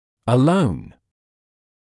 [ə’ləun][э’лоун]исключительно, только; одинокий; один, сам по себе, в одиночку